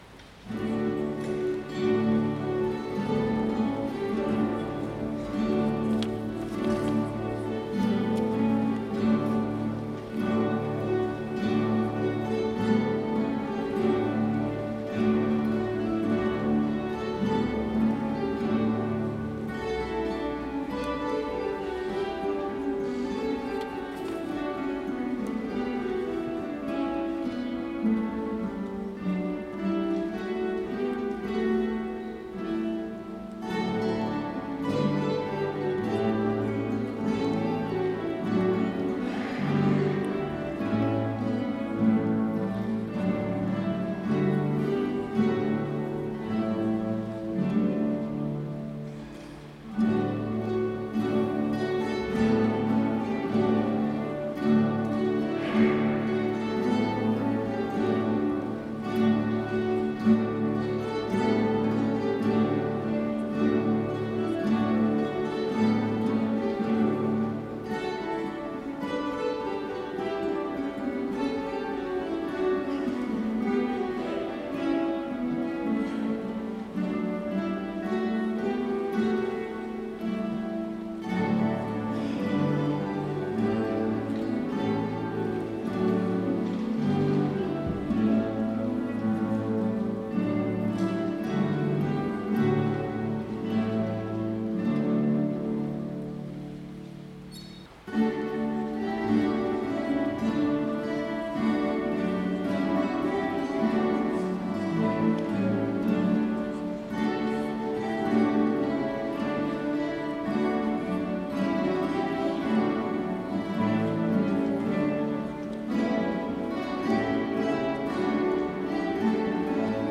Mit wunderbaren Chorklängen, sanften Gitarren- und Bandsounds, atmosphärischer orchestraler Sinfonik und Orgelmusik von der Empore stimmten uns die großen musikalischen Ensembles am 11. Dezember in St. Gabriel auf Weihnachten 2024 ein.
Gitarrenensemble